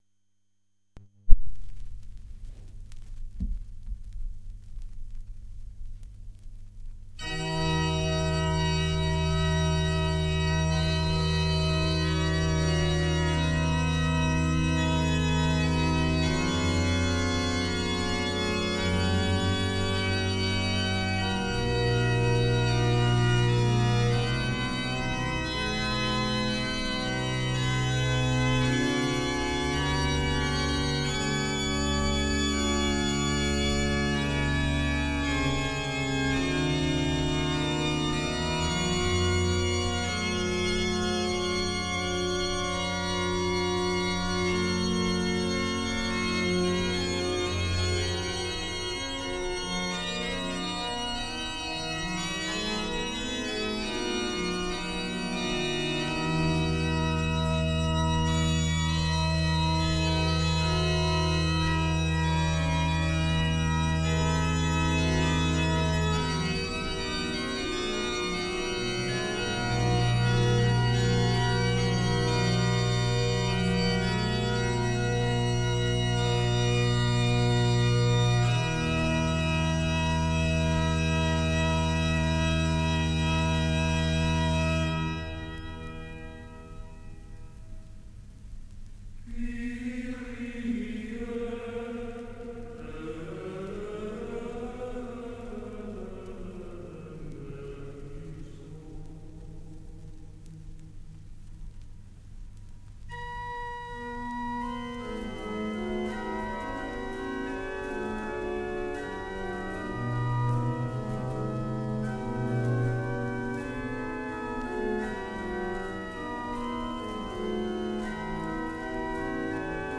Frescobaldi, Toccata, Kyrie-Christe-Kyrie (dai Fiori musicali, per organo).mp3 — Laurea Magistrale in Culture e Tradizioni del Medioevo e del Rinascimento